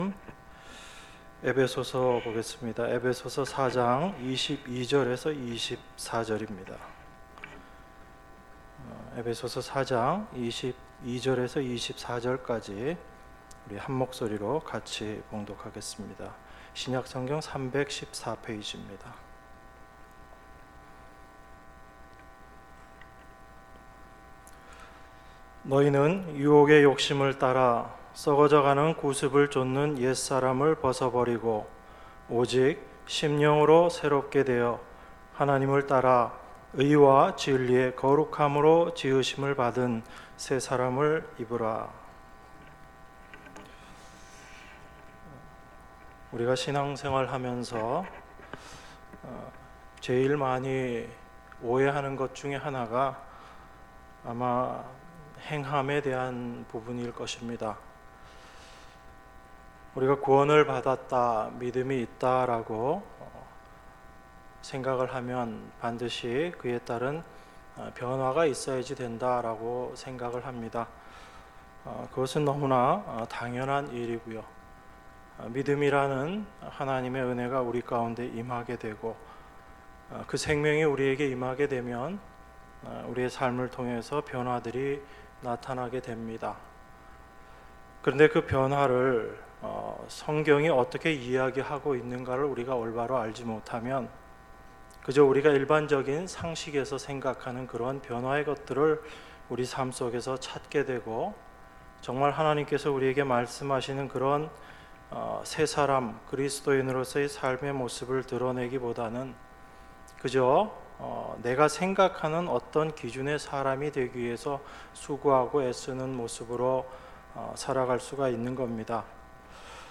수요예배 에베소서 4:22-24